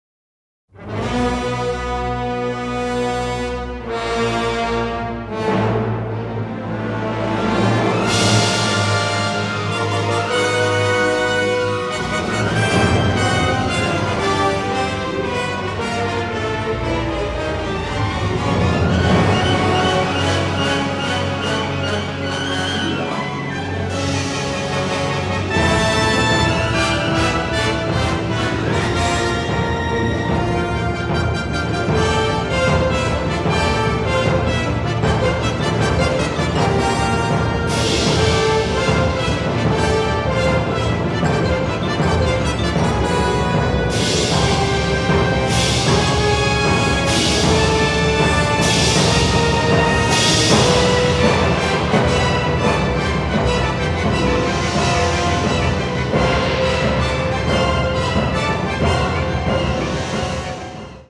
a rerecording of music